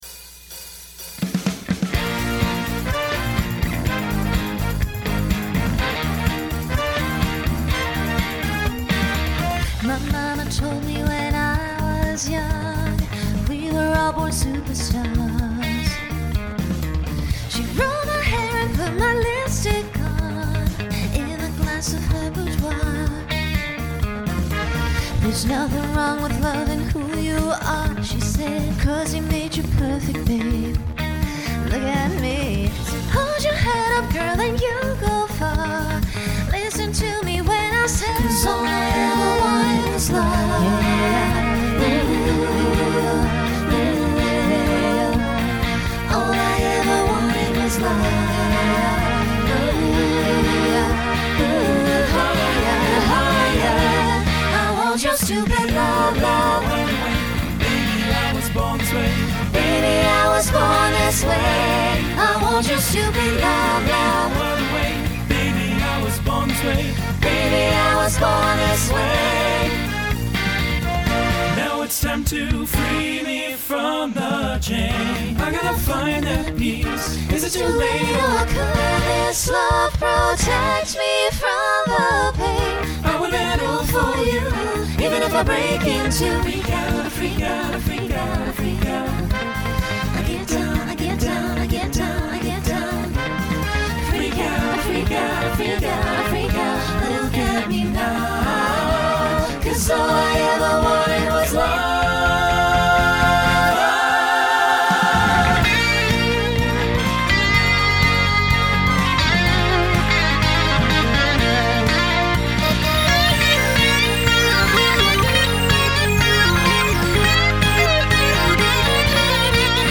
Genre Pop/Dance
Voicing SATB